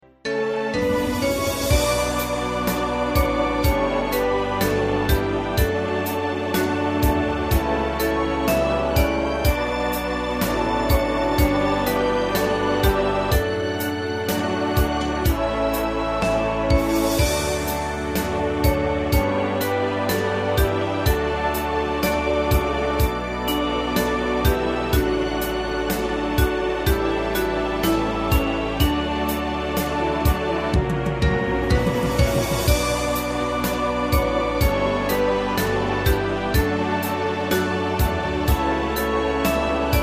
大正琴の「楽譜、練習用の音」データのセットをダウンロードで『すぐに』お届け！